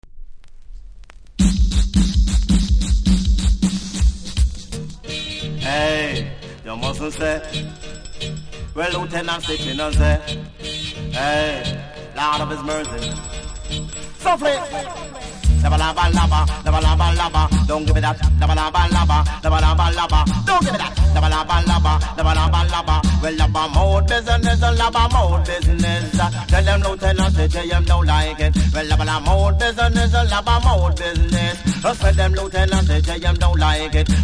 REGGAE 80'S
プレス時にラベルの紙が溝にかかりフェードアウト時にノイズ拾います。
多少うすキズありますが音は良好なので試聴で確認下さい。